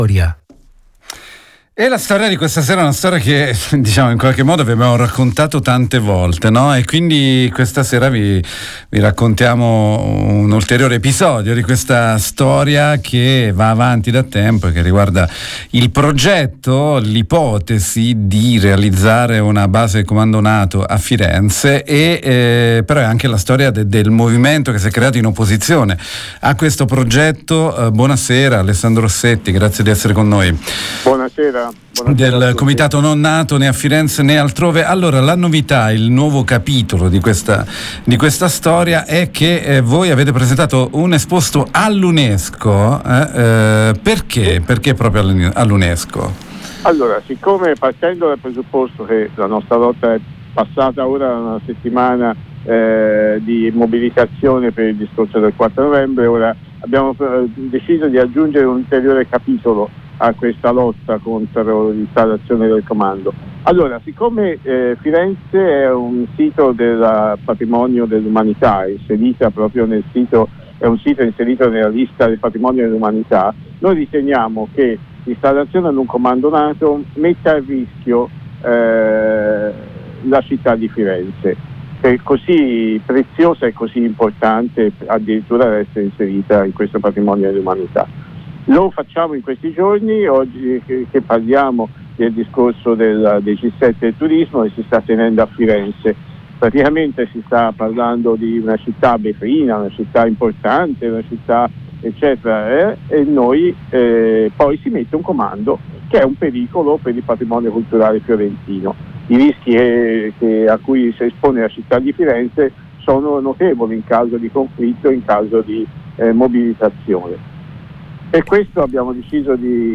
L’iniziativa si inserisce nel quadro delle azioni di protesta organizzate in occasione del G7 del Turismo, evento che si terrà a Firenze il 13, 14 e 15 novembre. Intervista